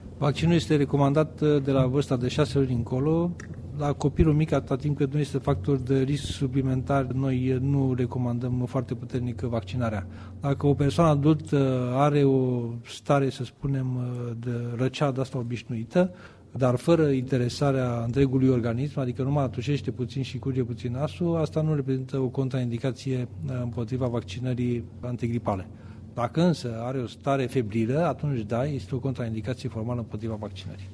Profesor doctor Adrian Streinu Cercel: